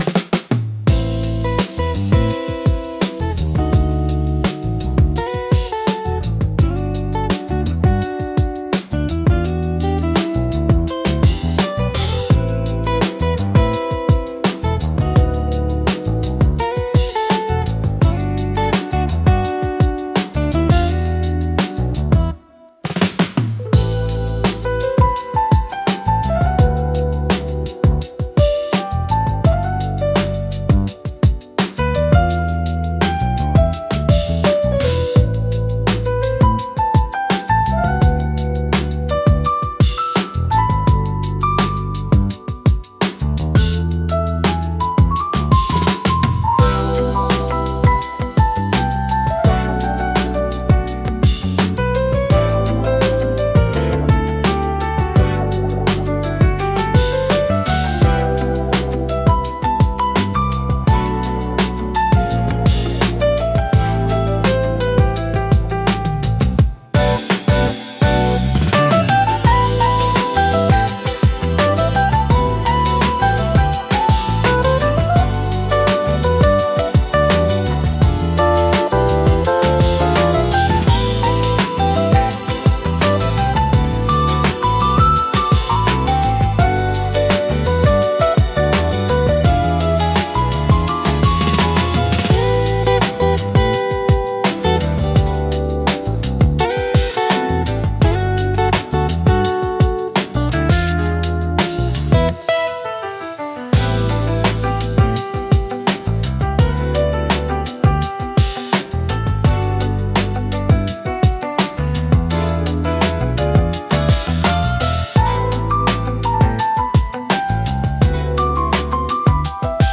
ラウンジは、洗練された落ち着いた雰囲気を持つジャンルで、ゆったりとしたテンポと滑らかなサウンドが特徴です。
ジャズやボサノバ、エレクトロニカの要素が融合し、都会的でリラックスした空間を演出します。
リラックス効果が高く、会話の邪魔をせず心地よい背景音として居心地の良さを高めます。